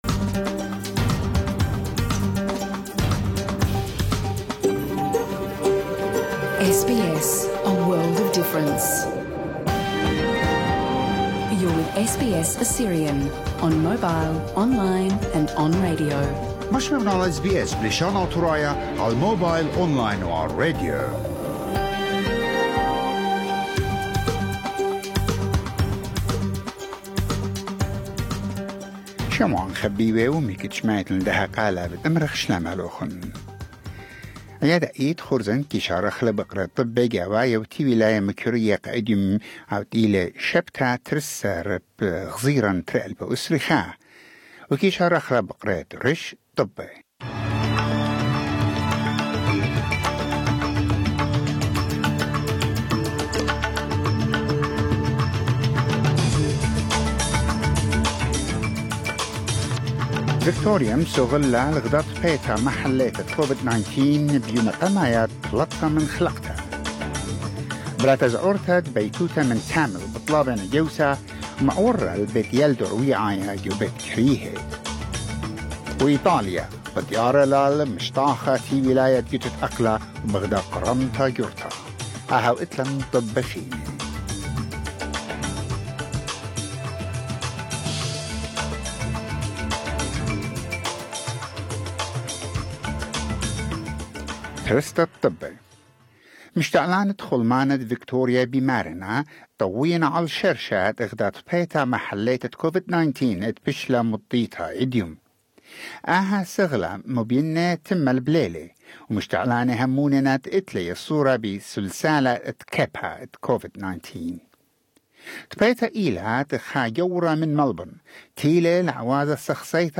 SBS NEWS IN ASSYRIAN 12 JUNE 2021